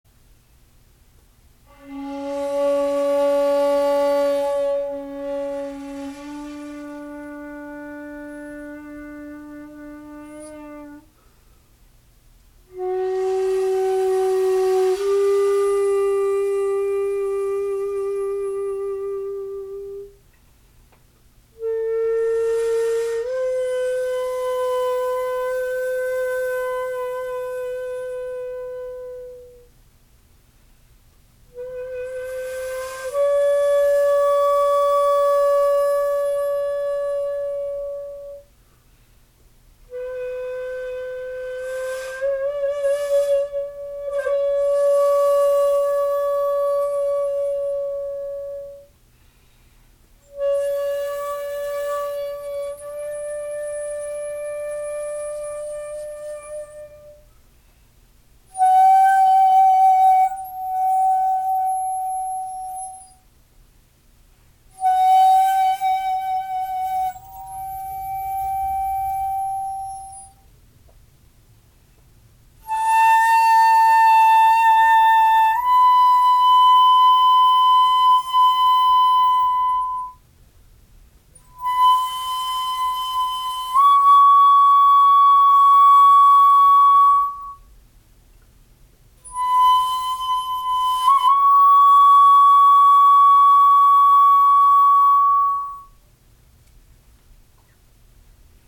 ピッチピッチ（音程）と喧しく叫ばれる前の時代ですので現代管よりは音が低めですが、その分、落ち着いた響きです。
録音はしていますが、所詮機械ですので生の音にはほど遠いことをご留意ください。
b.ッメリなどの半音－＞ほとんど音色の劣化なしで柔らかい音色の半音です。